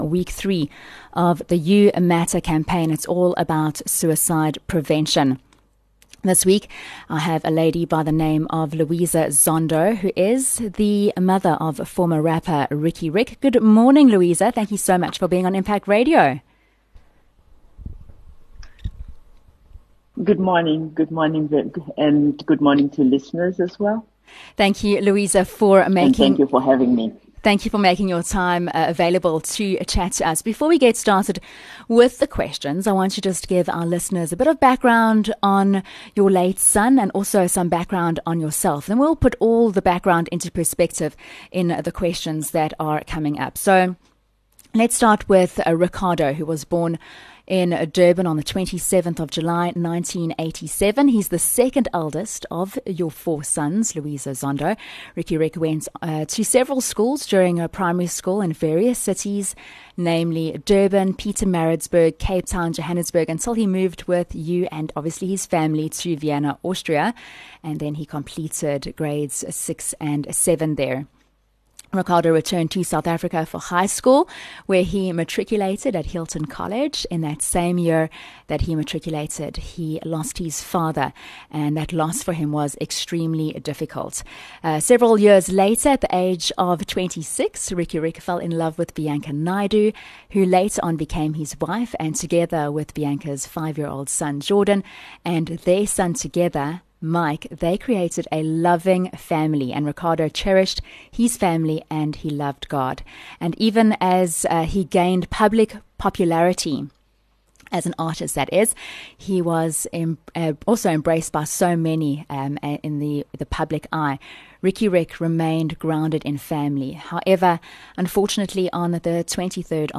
Suicide Prevention Interview